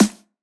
drum-slidertick.wav